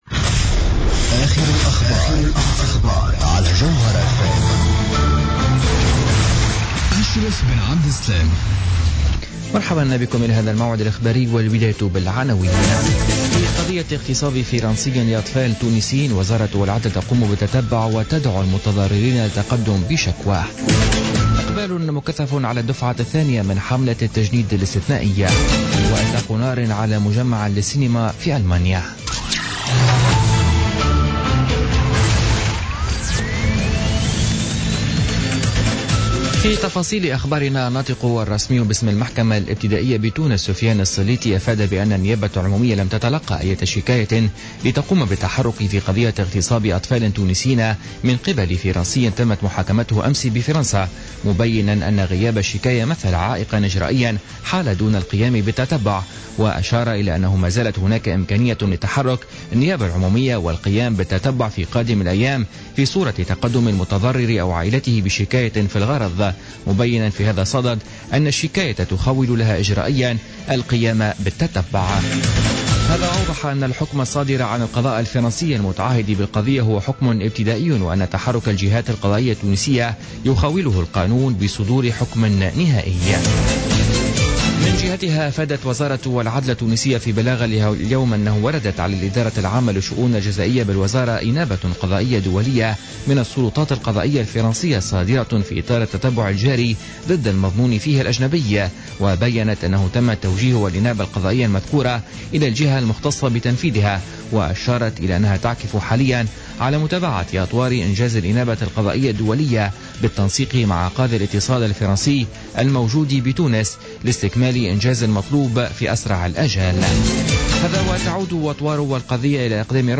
نشرة أخبار الخامسة مساء ليوم الخميس 23 جوان